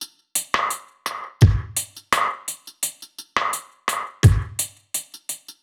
Index of /musicradar/dub-drums-samples/85bpm
Db_DrumKitC_Dry_85-03.wav